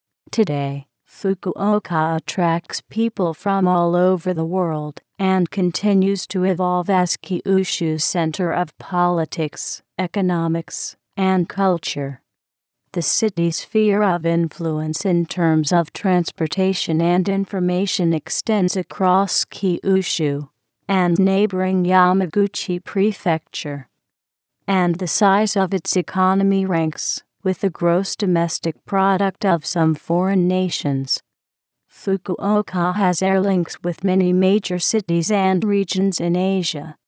注：上表の音声データはTextAloudによるコンピュータ合成音です。